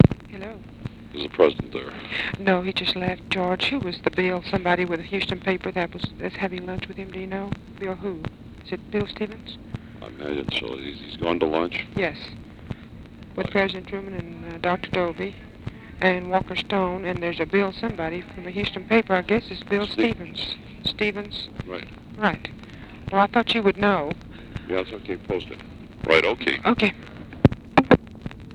Conversation with GEORGE REEDY
Secret White House Tapes | Lyndon B. Johnson Presidency